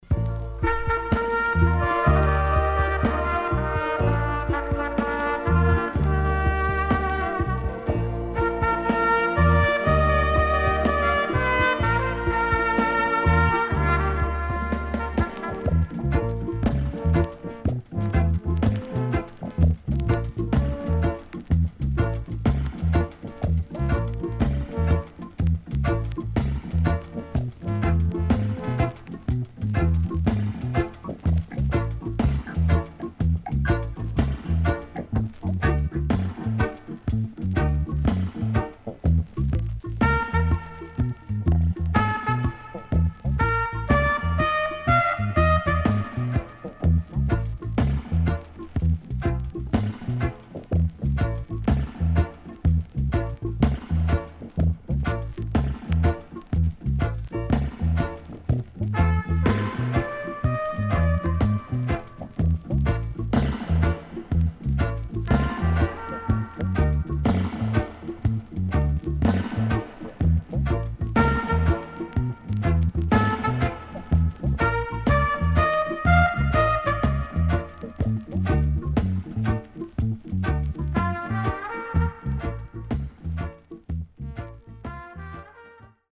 CONDITION SIDE A:VG(OK)〜VG+
Rare.Nice Deejay & Inst
SIDE A:所々チリノイズ、プチノイズ入ります。